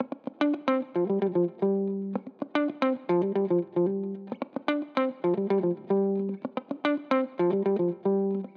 06 Guitar PT1.wav